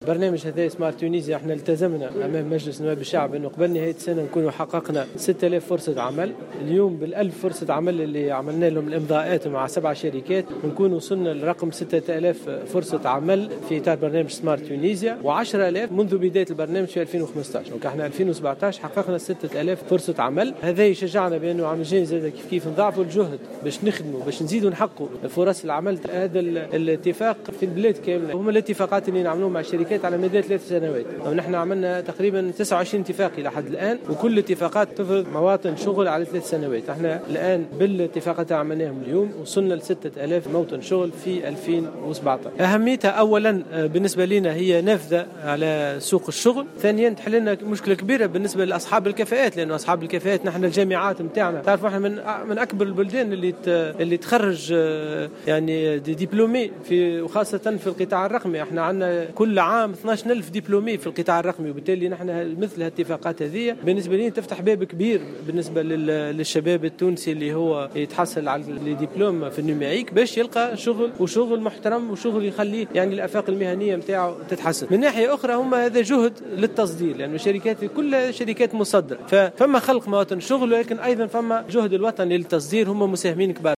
وأضاف الوزير في تصريح لمراسل "الجوهرة أف أم" أن من أهم المعارك التي تخوضها حكومة الوحدة الوطنية هي معركة التشغيل وإيجاد فرص شغل خاصة لأصحاب الكفاءات في الجهات الداخلية، وفق تعبيره.